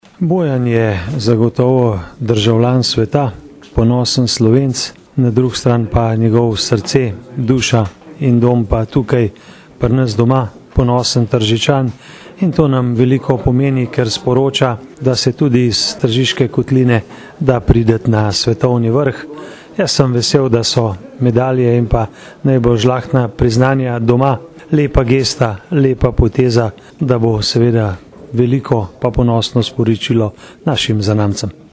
izjava_mag.borutsajoviczupanobcinetrzic_krizajevastena.mp3 (767kB)